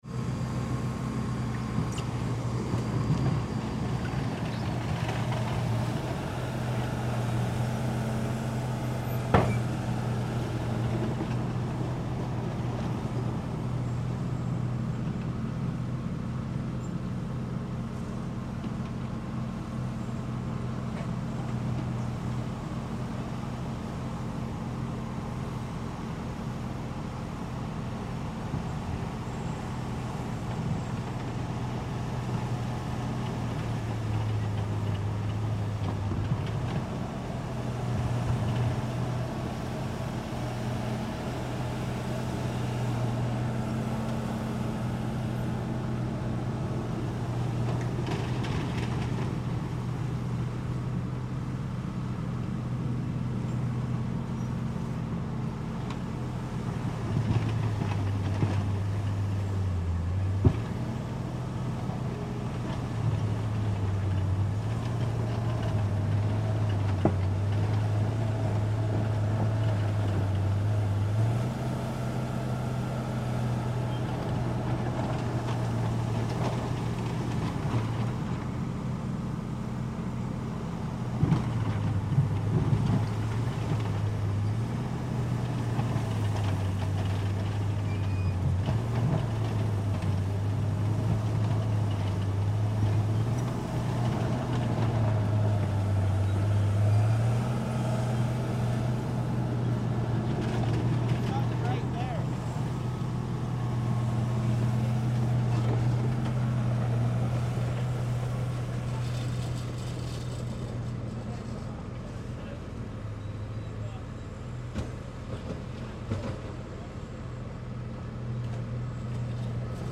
Шум в кабине бульдозера во время работы